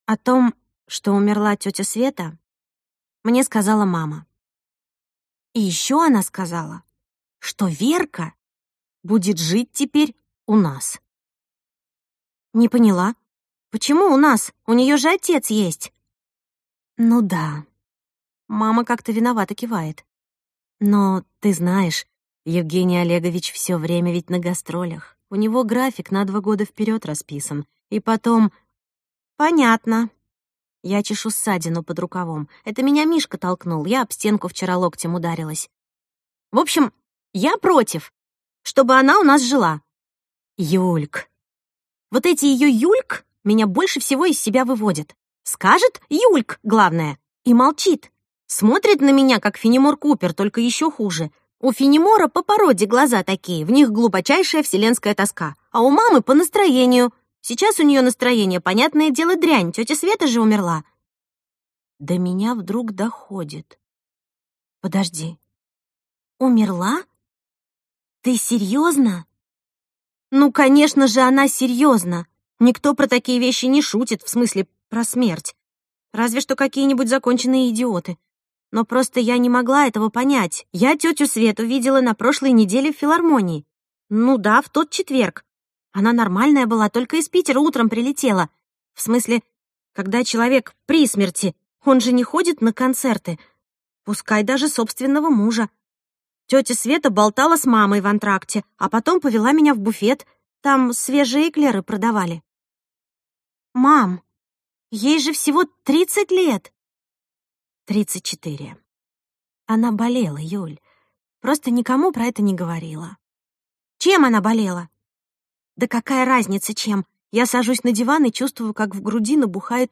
Аудиокнига Я уеду жить в «Свитер» | Библиотека аудиокниг
Прослушать и бесплатно скачать фрагмент аудиокниги